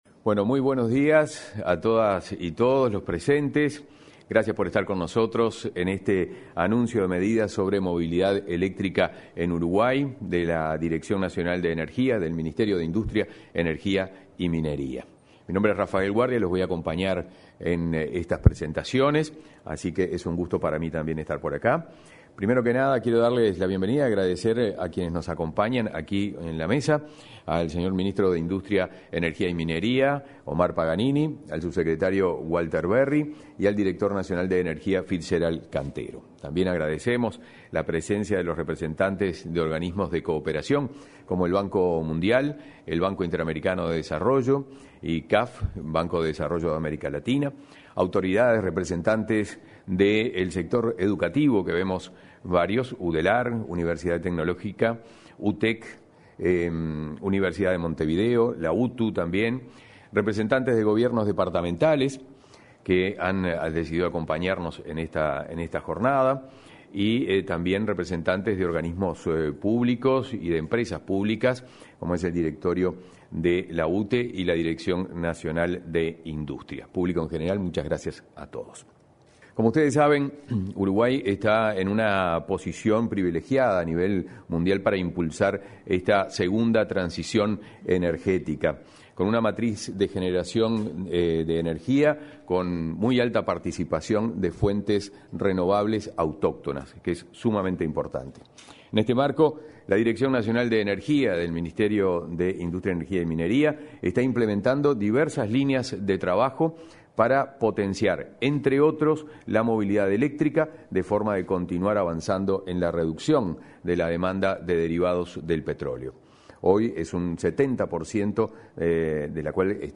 Este 1.° de junio, en el salón de actos de la Torre Ejecutiva, el Ministerio de Industria, Energía y Minería anunció medidas sobre movilidad eléctrica
Estuvieron presentes el titular y el subsecretario de esa cartera, Omar Paganini y Walter Verri, respectivamente, y el director Nacional de Energía, Fitzgerald Cantero.